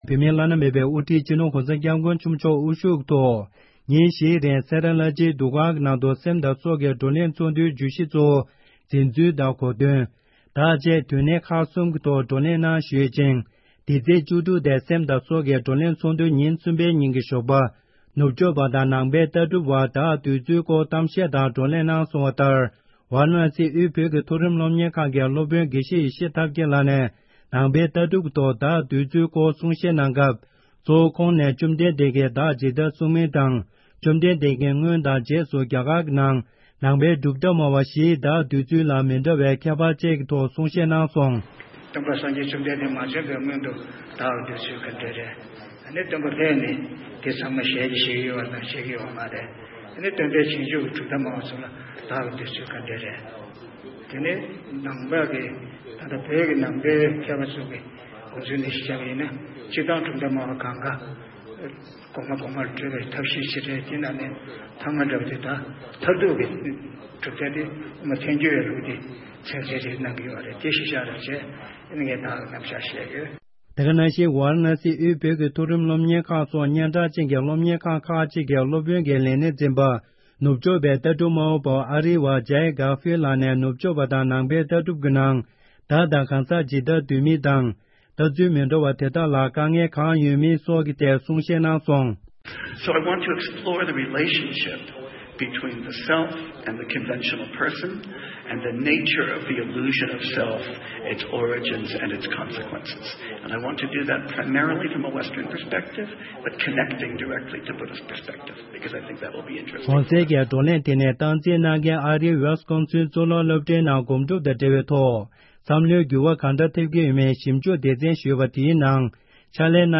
སེམས་དང་སྲོག་གི་ཚོགས་འདུ་ཉིན་གསུམ་པ། བགྲོ་གླེང་།